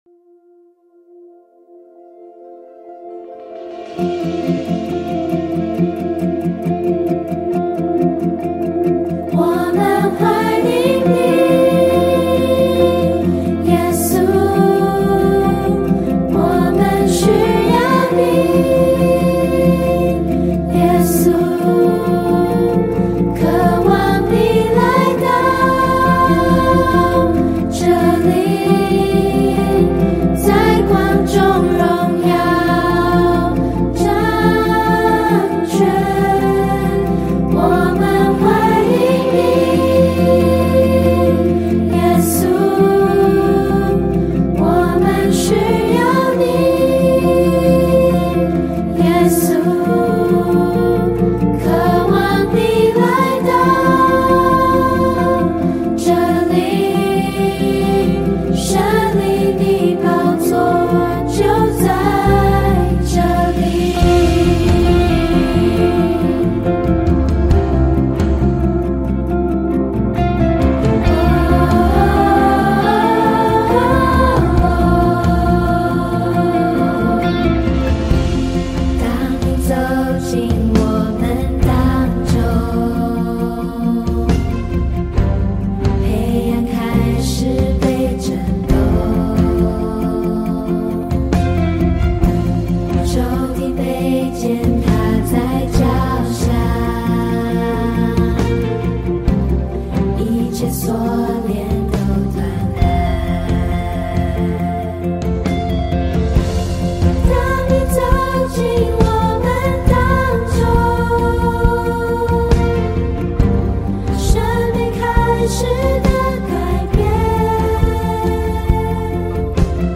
儿童赞美诗 | 当祢走进我们当中